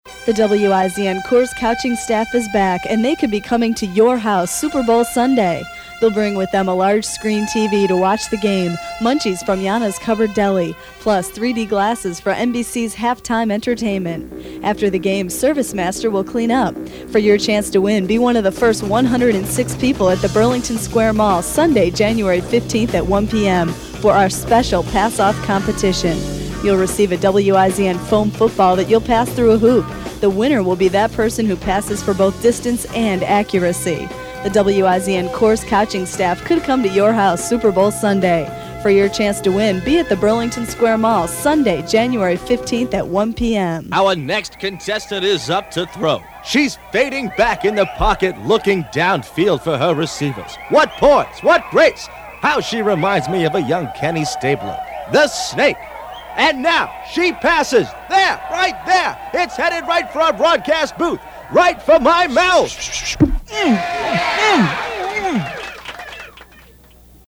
SIDE A - PROMOS